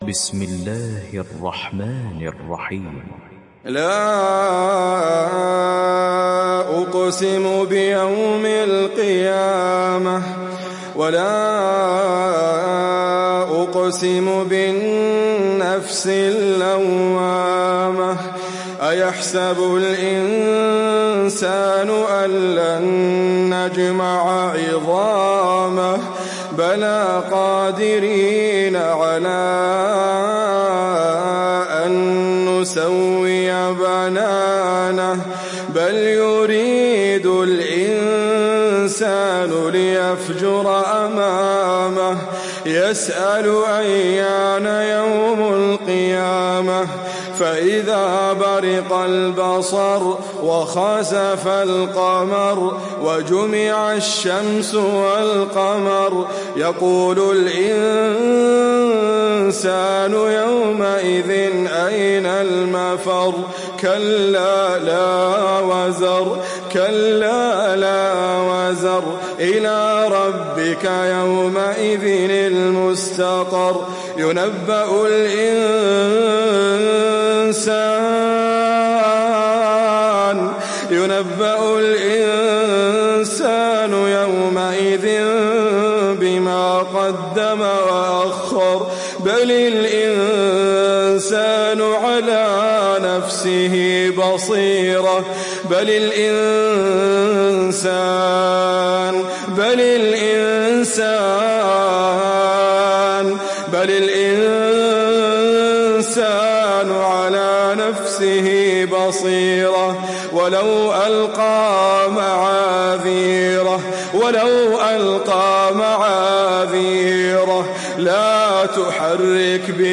دانلود سوره القيامه mp3 إدريس أبكر روایت حفص از عاصم, قرآن را دانلود کنید و گوش کن mp3 ، لینک مستقیم کامل